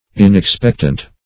Inexpectant \In"ex*pect"ant\, a.